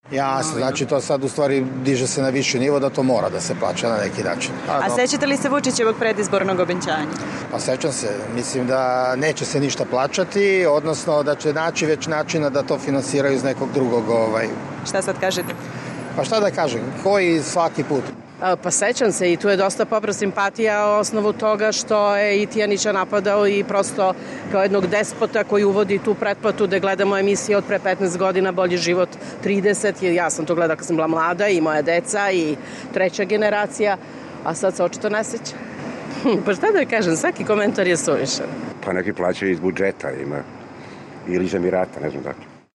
Građani o pretplati